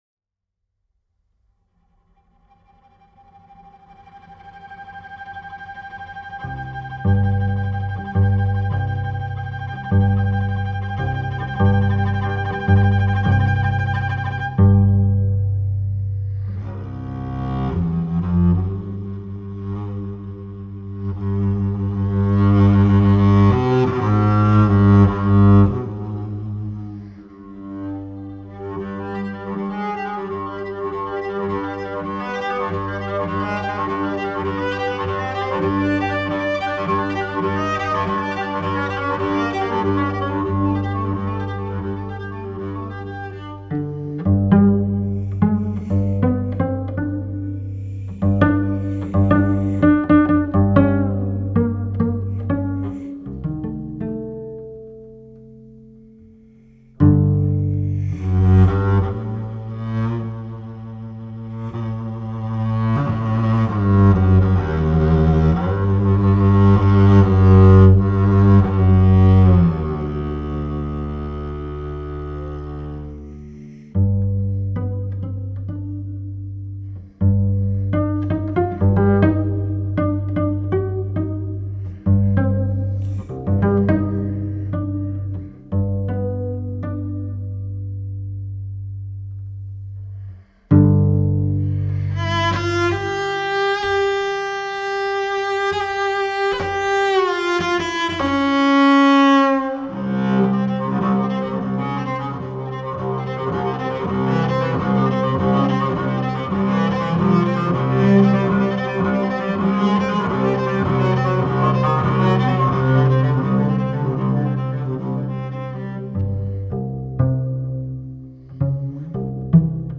Notes de violoncelle divines.